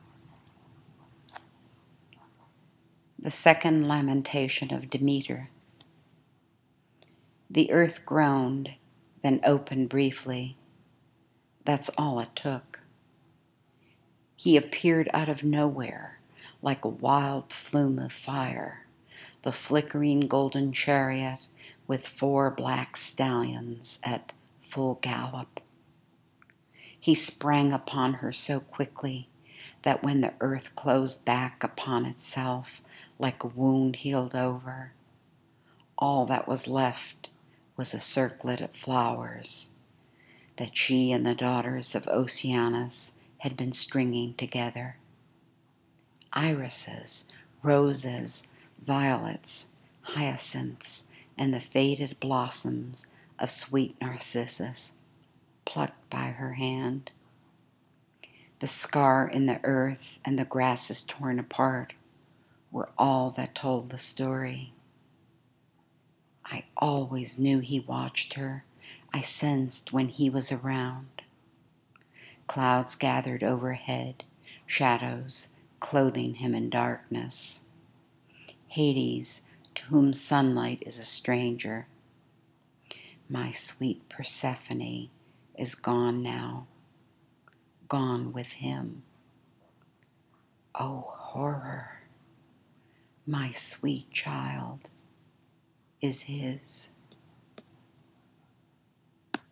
To hear me read this poem, please click on the link below and wait a few seconds for it to begin.
My style of reading isn’t dramatic, and I do try hard to avoid “poet voice,” something I dislike very much. Hopefully my readings are pretty natural, maybe too natural for those who do like more drama.